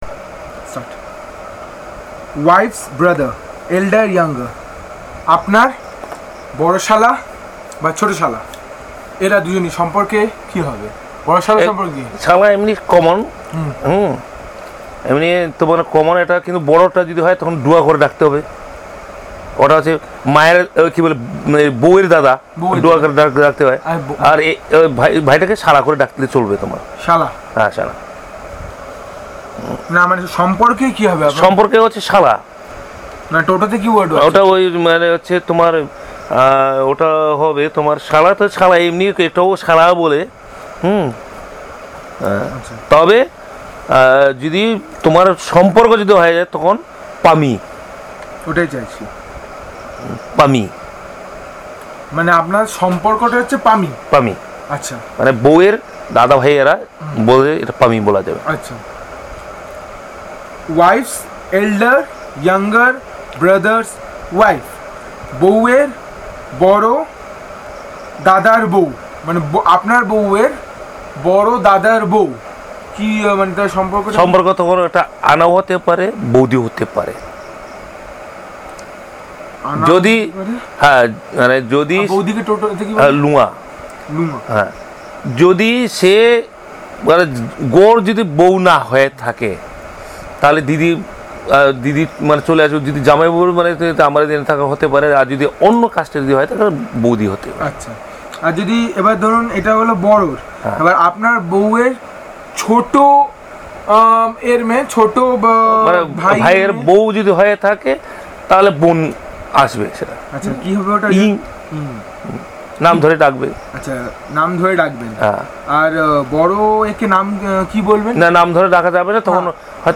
Elicitation of words about kinship terms, stages of life, domestic animals, wild animals, flies and insects, aquatic and aerial animals, health ailments, human body parts